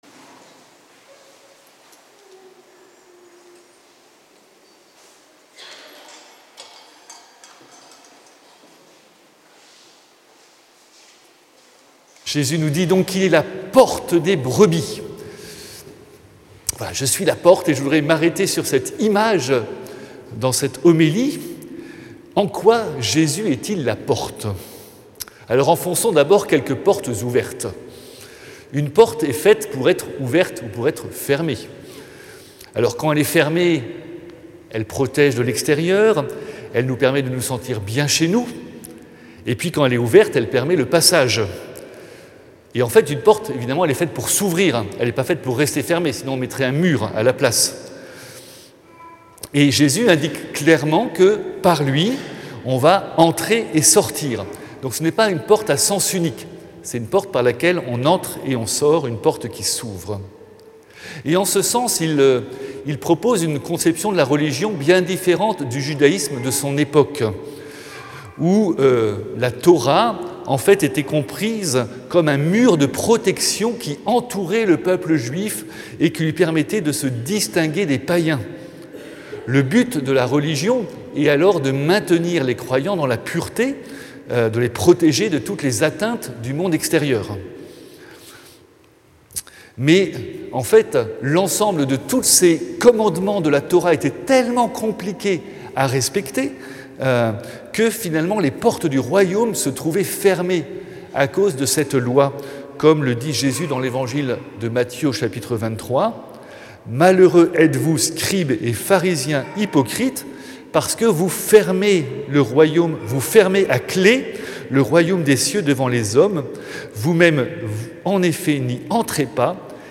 Audio : Homélie du 4e dimanche de Pâques - 25 avril 2026